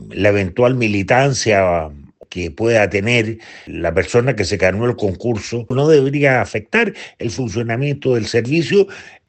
El exministro de Justicia, Jaime Campos, recalcó que el Servicio Médico Legal “siempre ha sido un organismo técnico, no político”.